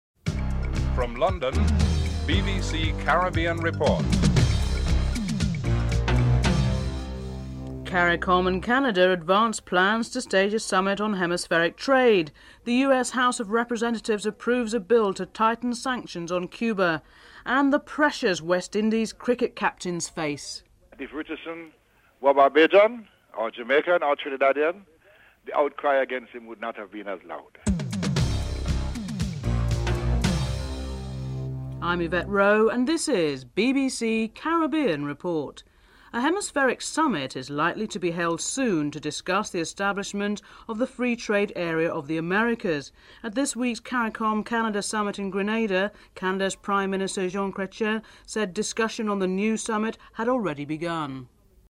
1. Headlines (00:00-00:35)